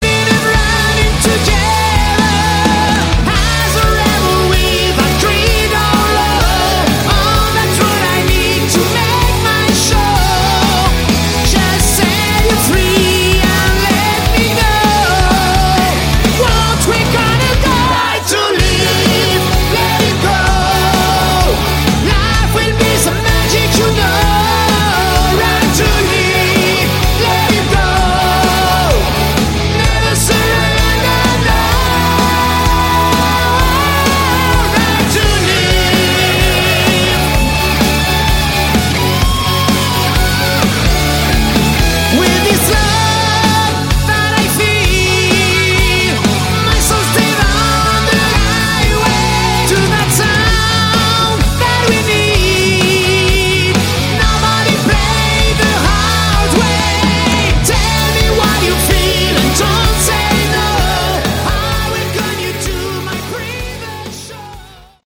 Category: Hard Rock
guitar
keyboards
lead vocals
bass
drums